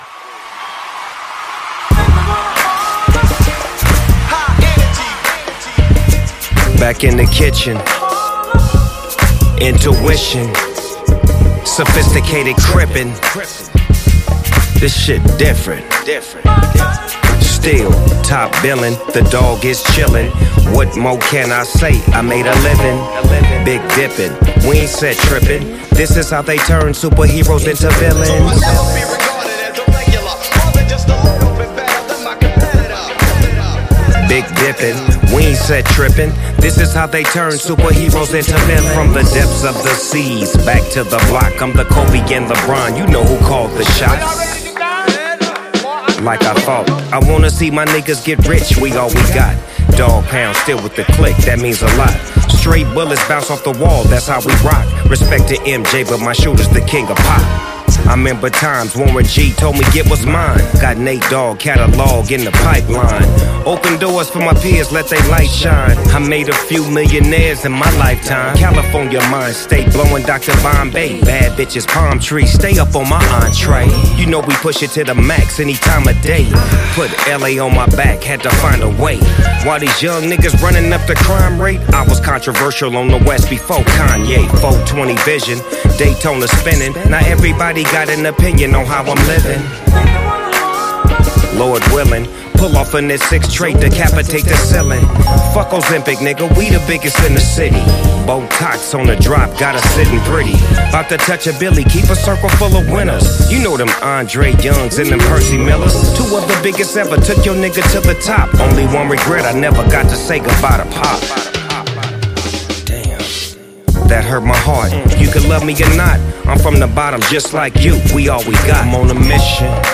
classic West Coast sound with smooth beats and cool lyrics
deep voice and relaxed flow
Fans of old-school hip-hop will love this album.